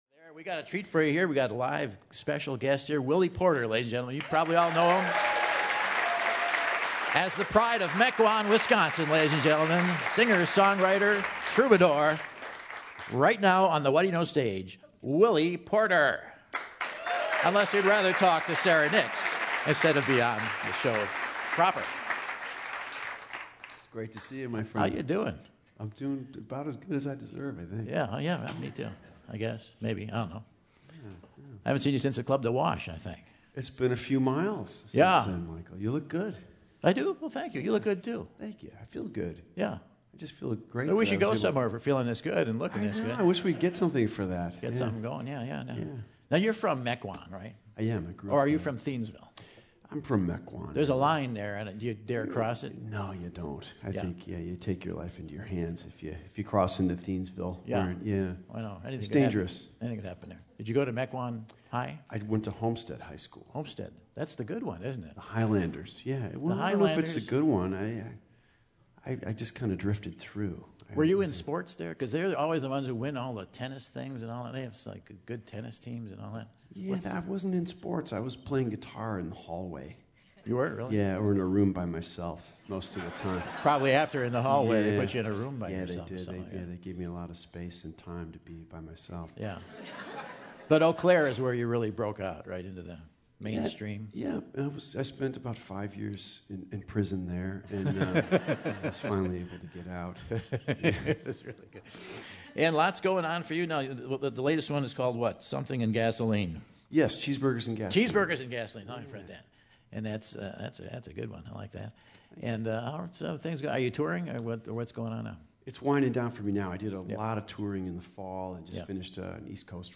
He performs "Yeah, I know" and gets the audience to join in on "How to Rob a Bank."